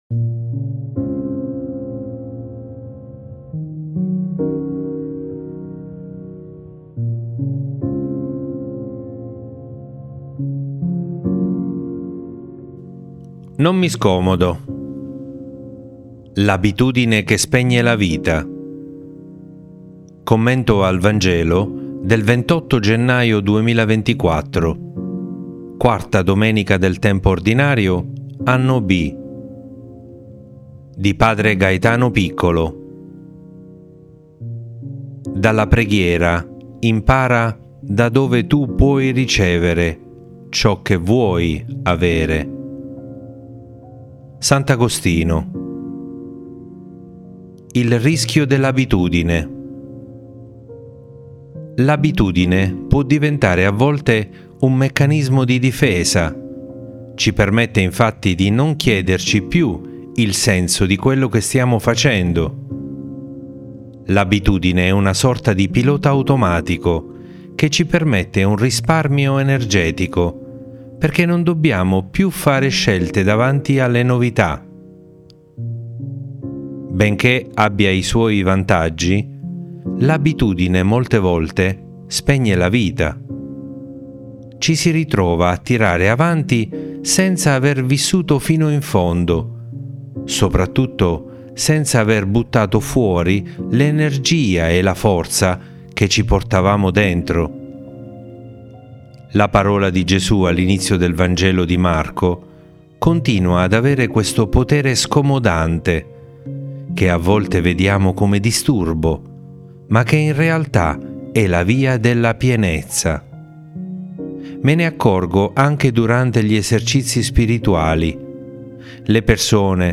Commento al Vangelo del 28 gennaio 2024